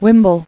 [wim·ble]